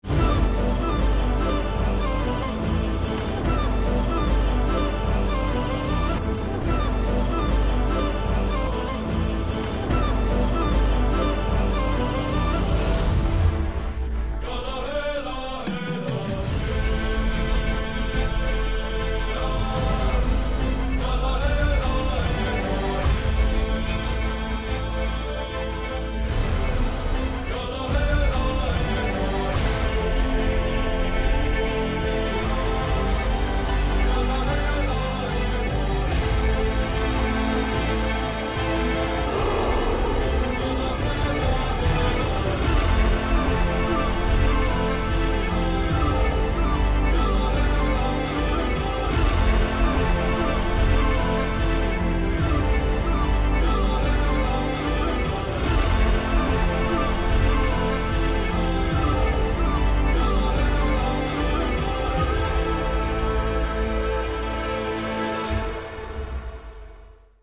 without dialogues and disturbances